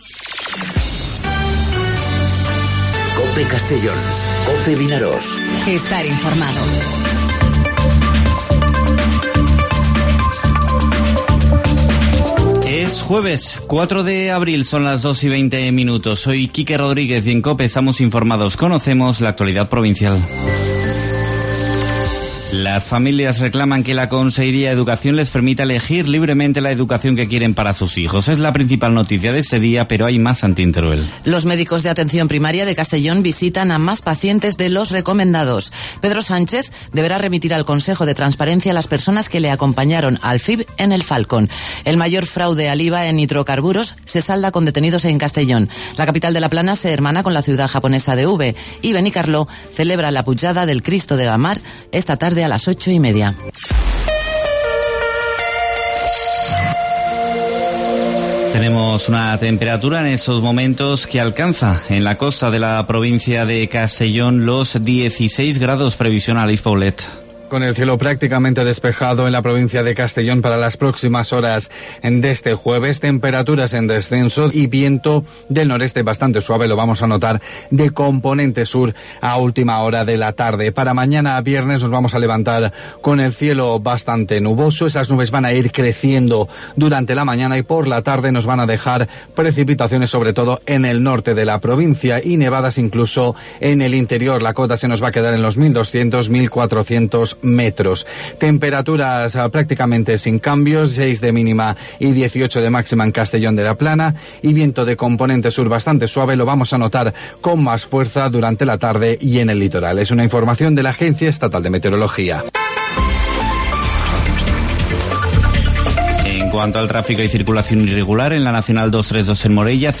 Informativo 'Mediodía COPE' en Castellón (04/04/2019)